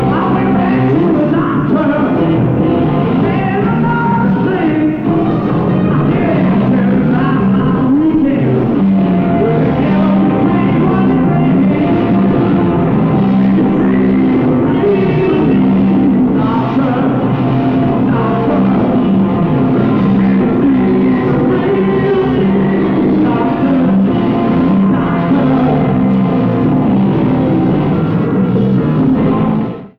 Format/Rating/Source: CD - G - Audience
Comments: Horrible audience recording.
Sound Samples (Compression Added):